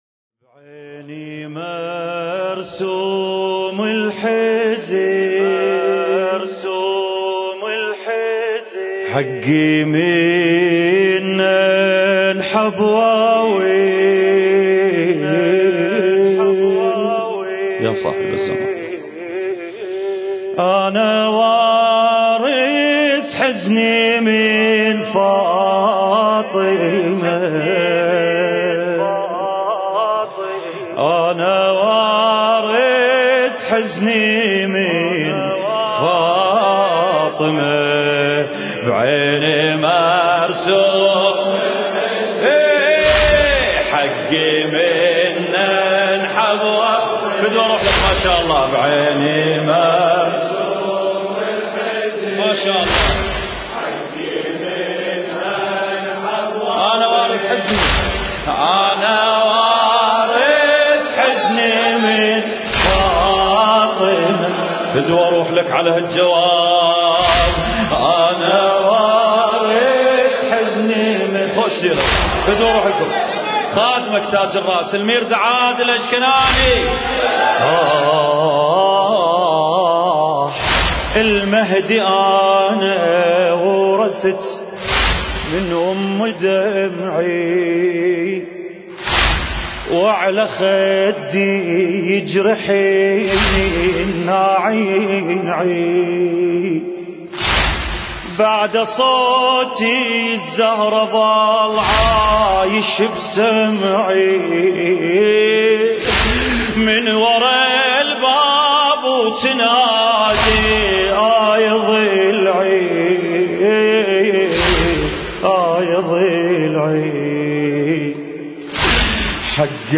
المكان: هيئة شباب الزهراء (عليها السلام)- حسينية أنصار الحسين (عليه السلام)